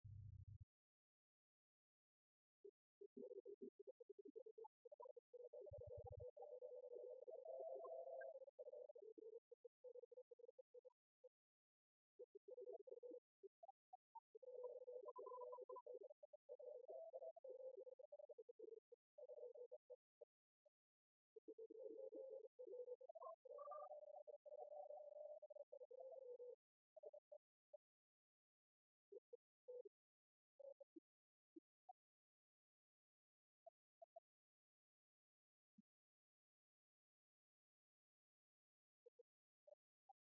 Concert spirituel
Chorales de Notre-Dame et de Saint-Martin
spectacle, concert ; croyance religieuse
Beaupreau
Pièce musicale inédite